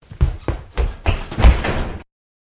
Звуки бегущего человека
Звук человека выбежавшего из дома на огромной скорости